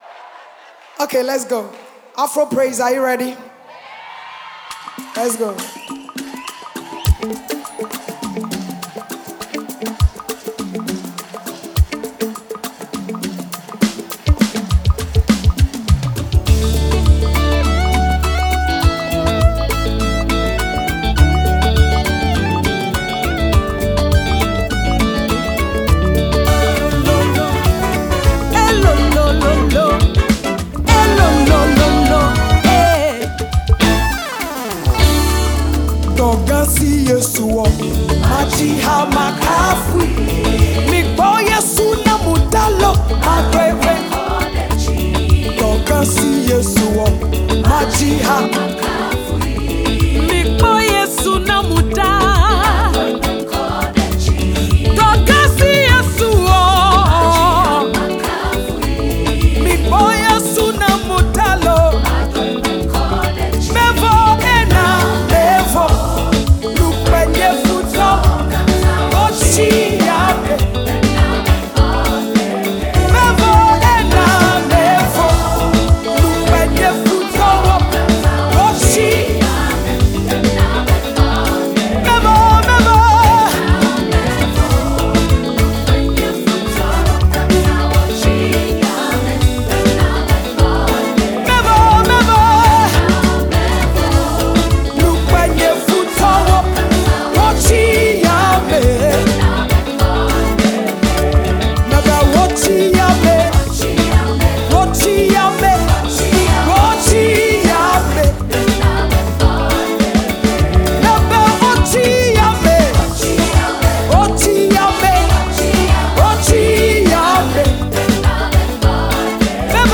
praise medley